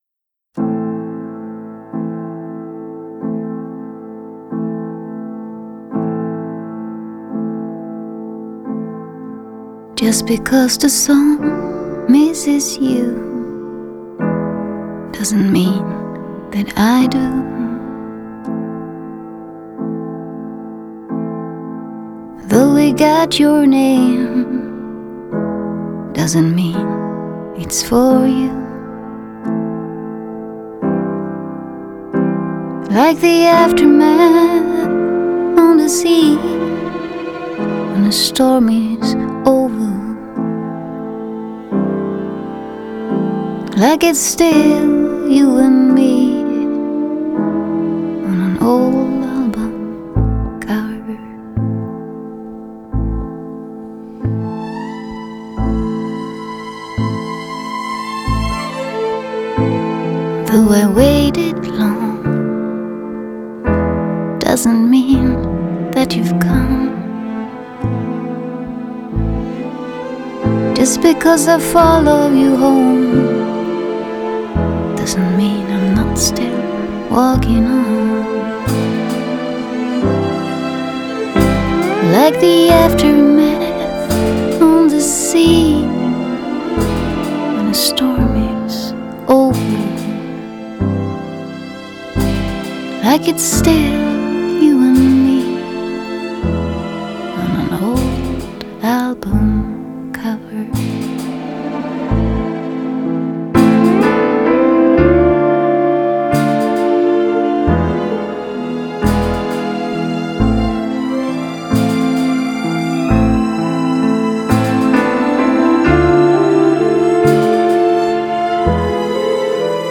장르: Rock
스타일: Acoustic